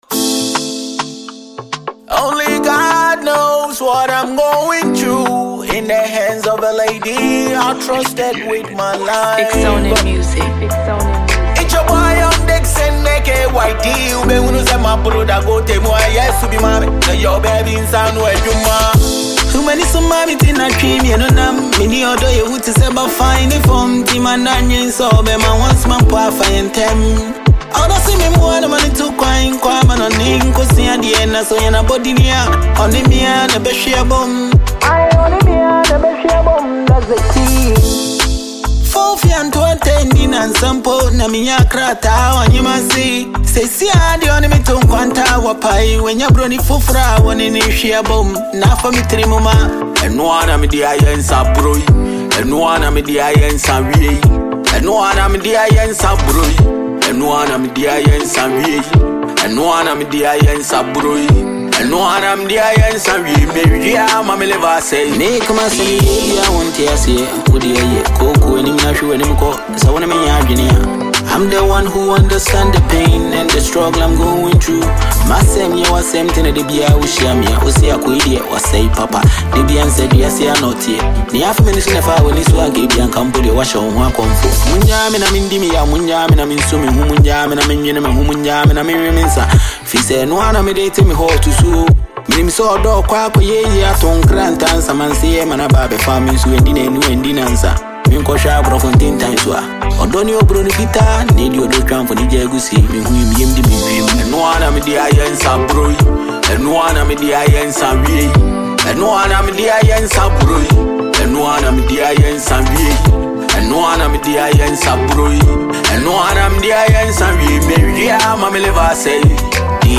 Amazing Ghanaian rapper and song writer